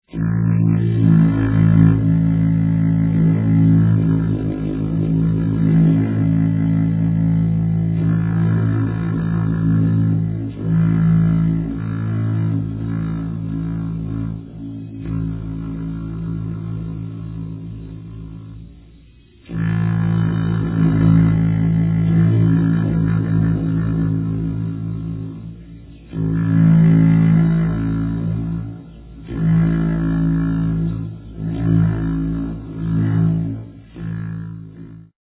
Long Trumpets